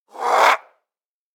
DayZ-Epoch/SQF/dayz_sfx/zombie/spotted_1.ogg at e034c6efe50da50378a17c95bc816cffe138113d